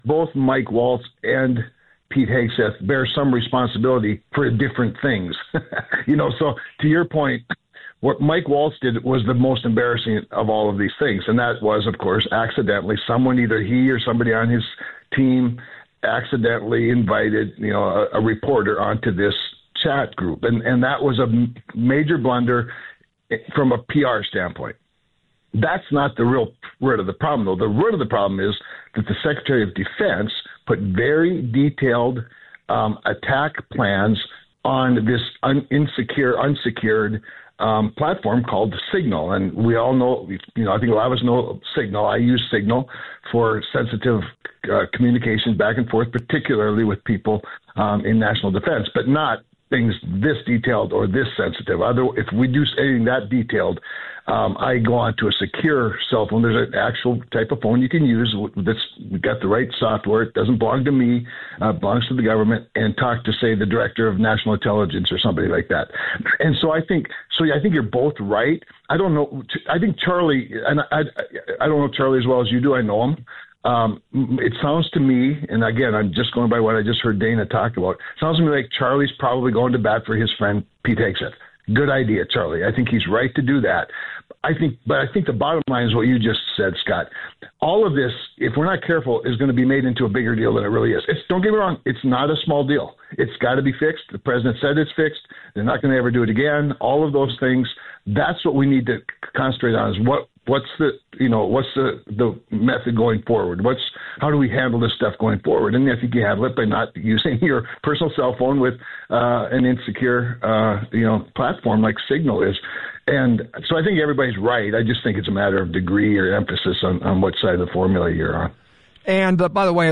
cramer-intv-for-midday.mp3